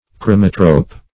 chromatrope - definition of chromatrope - synonyms, pronunciation, spelling from Free Dictionary
Chromatrope \Chro"ma*trope\, n. [Gr.